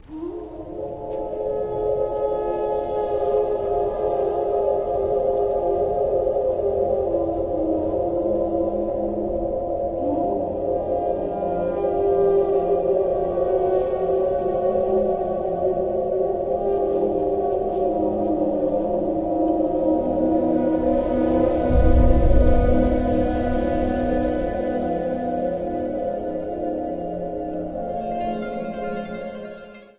sekcja rytmiczna
na gitarze basowej
na perkusji
zagrał na fortepianach elektrycznych i syntezatorach